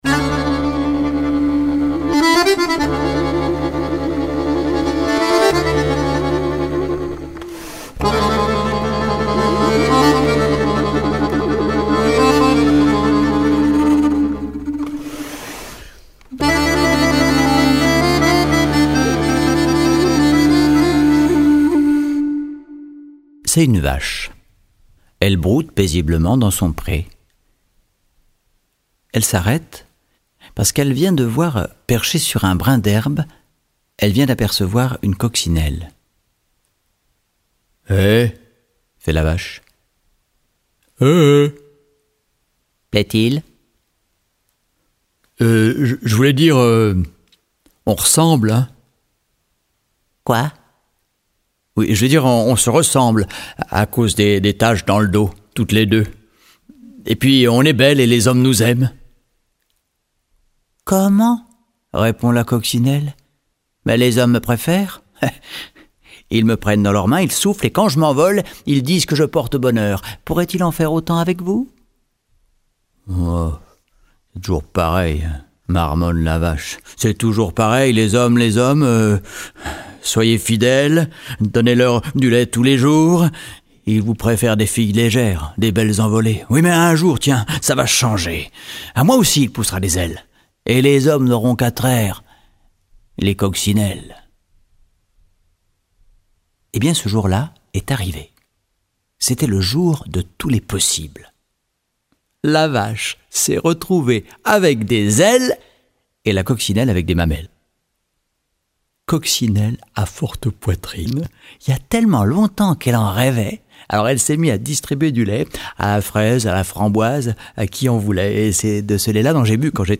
2019-10-18 Des histoires pour rire, des récits étranges, des aventures loufoques et des balades sans queue ni tête : voilà ce que nous réserve cet album. Pour nous faire sourire ou nous faire rêver, conteuses et conteurs nous invitent avec bonheur et délectation à nous plonger dans l’absurde, le fantastique et nous embarquent pour la journée de tous les possibles.